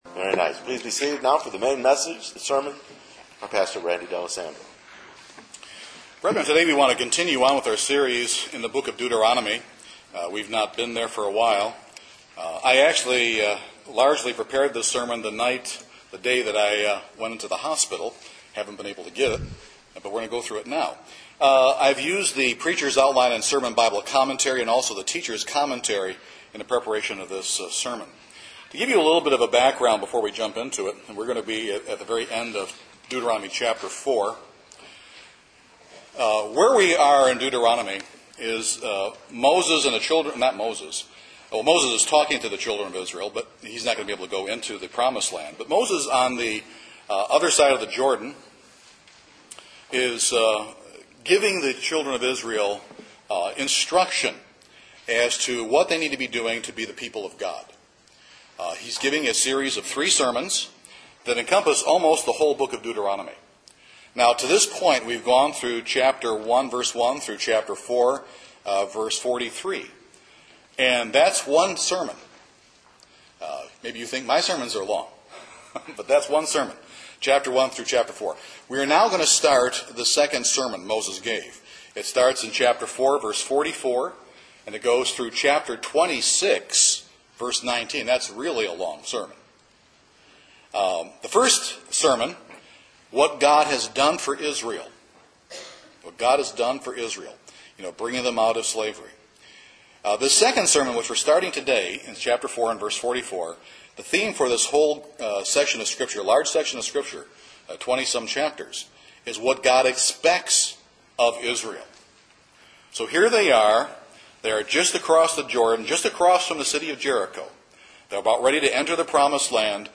The Ten Commandment are composed of eternal, life giving and life guiding principles God has given us to assist us in our daily walk with Him. In this sermon, and next week’s, we want to examine those eternal, life giving and life guiding principles behind each of the Ten Commandments.